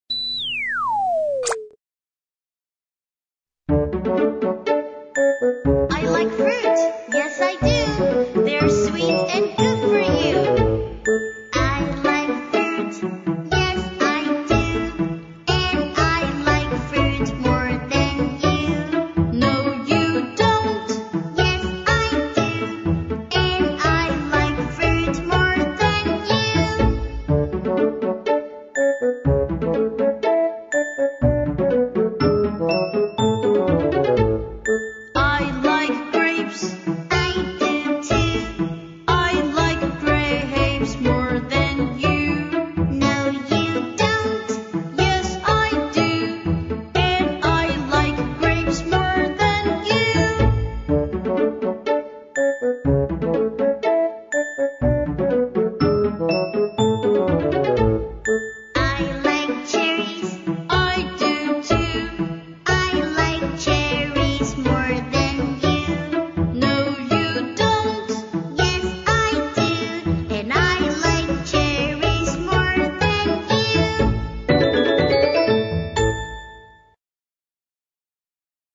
在线英语听力室英语儿歌274首 第82期:I like fruit的听力文件下载,收录了274首发音地道纯正，音乐节奏活泼动人的英文儿歌，从小培养对英语的爱好，为以后萌娃学习更多的英语知识，打下坚实的基础。